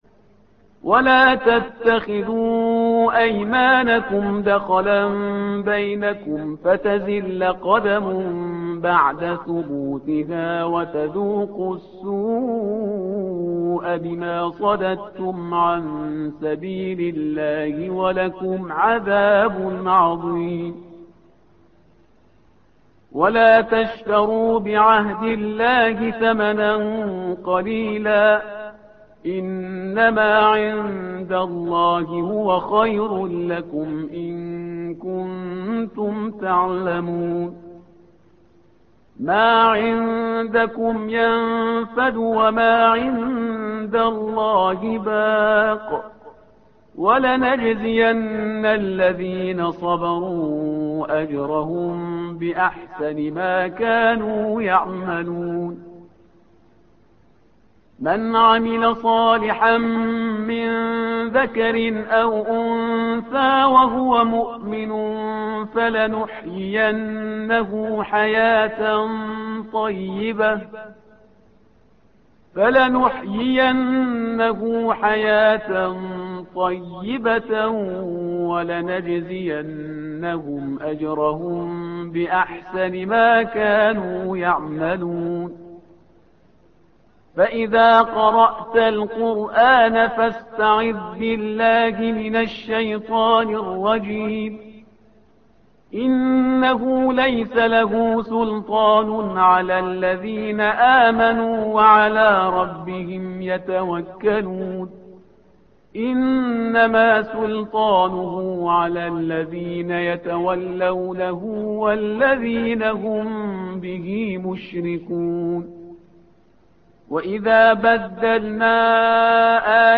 الصفحة رقم 278 / القارئ